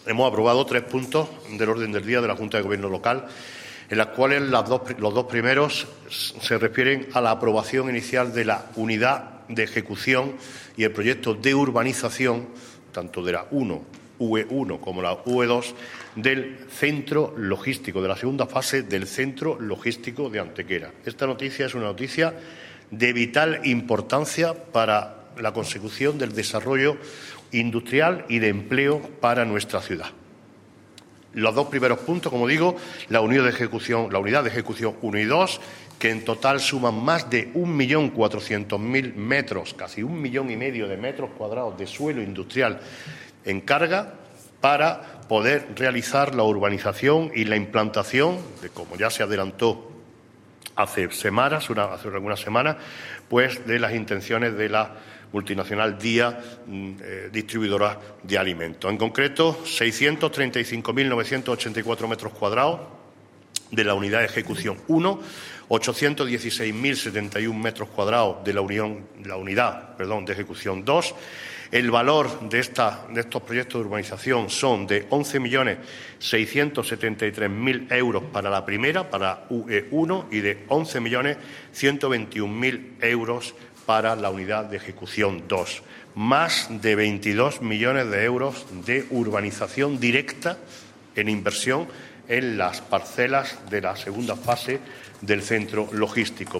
El alcalde de Antequera, Manolo Barón, acompañado por la teniente de alcalde de Urbanismo, Teresa Molina, y del concejal delegado de Obras e Inversiones, José Ramón Carmona, ha informado hoy en rueda de prensa de la aprobación (jurídica, administrativa y urbanísticamente) de un millón y medio de metros cuadrados de nuevo suelo industrial solucionando así tras más de una década el bloqueo del Centro Logístico de Antequera.
Cortes de voz